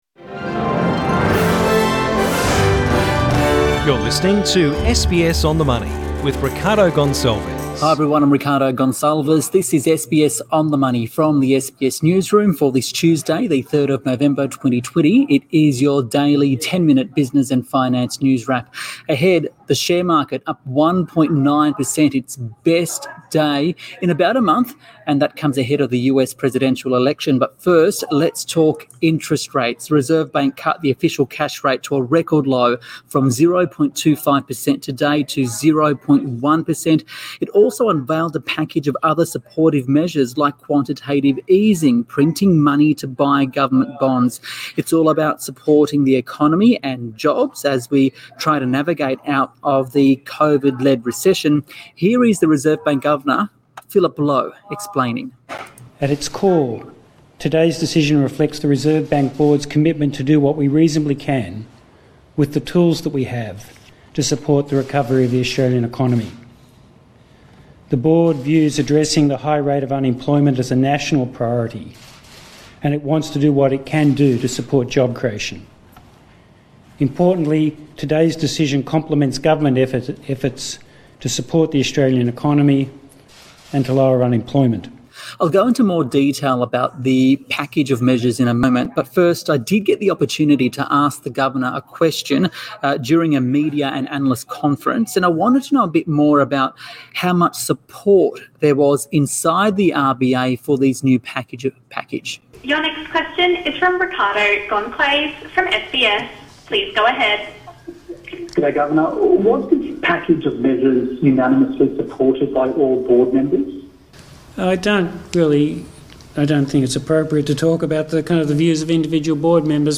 speaks with Reserve Bank Governor Philip Lowe